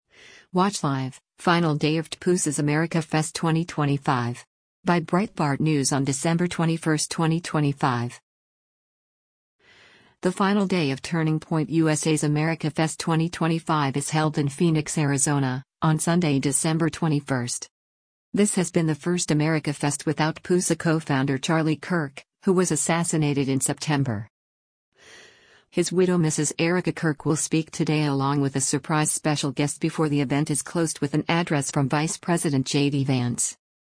The final day of Turning Point USA’s AmericaFest 2025 is held in Phoenix, AZ, on Sunday, December 21.
His widow Mrs. Erika Kirk will speak today along with a surprise “special guest” before the event is closed with an address from Vice President JD Vance.